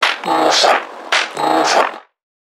NPC_Creatures_Vocalisations_Infected [86].wav